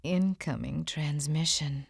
youvegotmail2.WAV